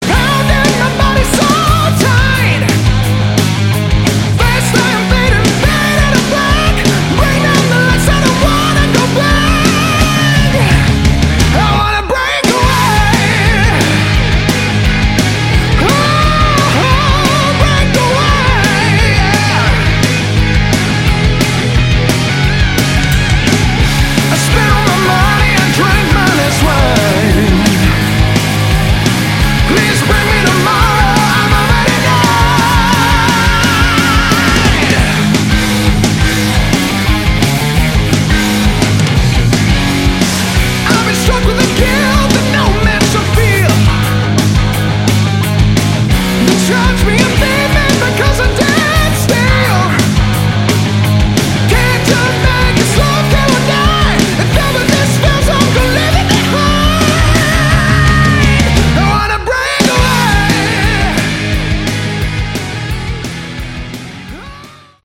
Category: Hard Rock
vocals
lead guitars
bass guitar
drums